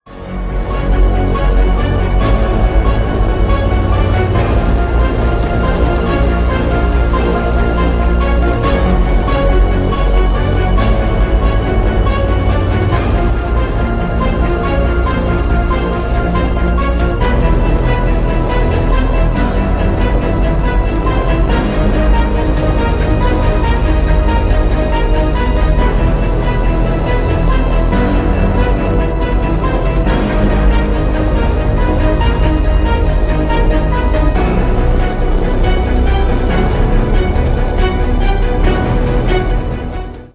Рок Металл
без слов